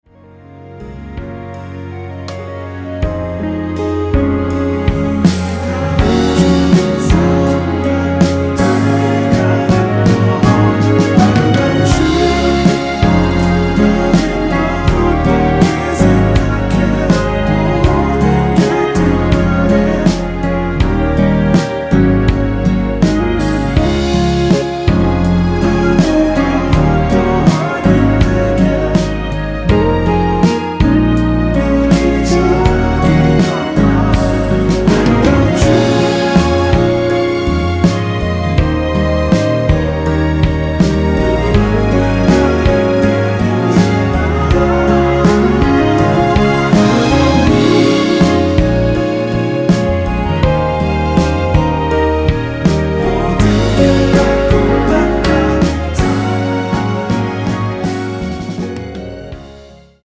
원키에서(-1)내린 멜로디와 코러스 포함된 MR입니다.(미리듣기 확인)
Db
앞부분30초, 뒷부분30초씩 편집해서 올려 드리고 있습니다.
중간에 음이 끈어지고 다시 나오는 이유는